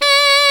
Index of /90_sSampleCDs/Roland L-CD702/VOL-2/SAX_Alto Short/SAX_Pop Alto
SAX C 4 S.wav